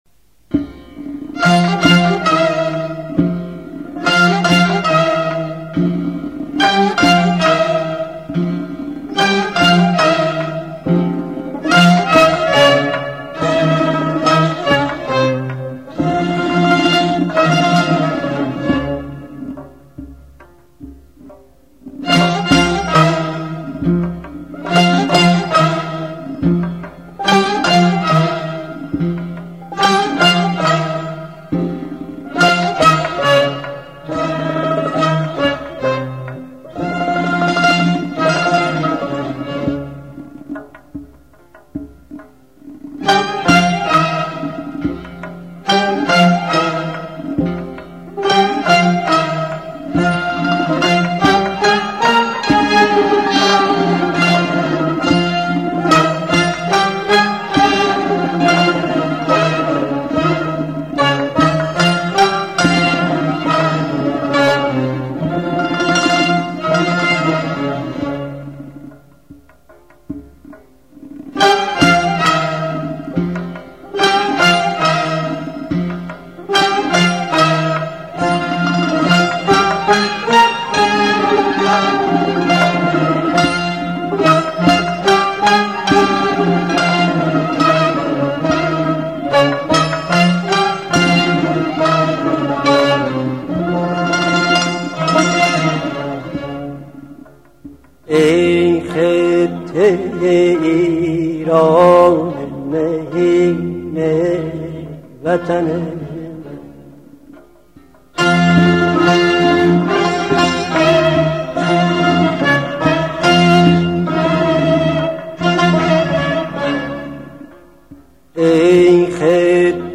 ببخشید اگه شاد نیست